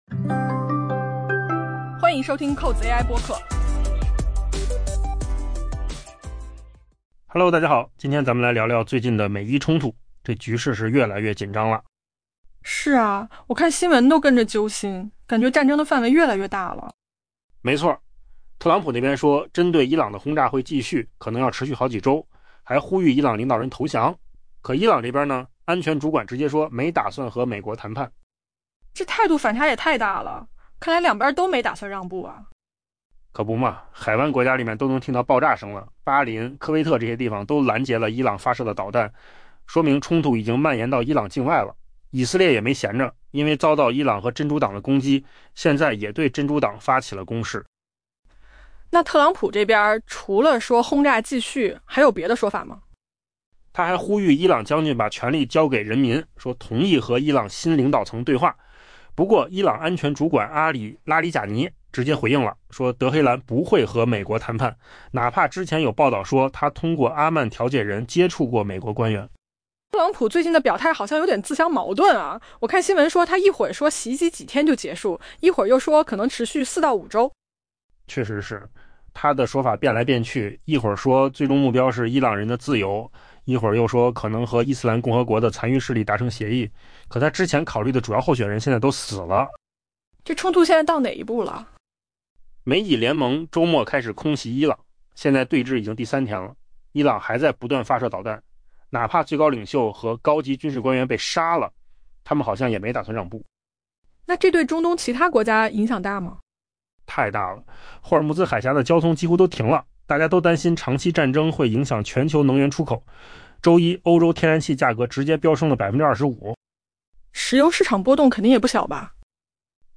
AI 播客：换个方式听新闻 下载 mp3 音频由扣子空间生成 美国总统特朗普表示，针对伊朗的轰炸行动将继续，可能长达数周，并呼吁该国领导人投降。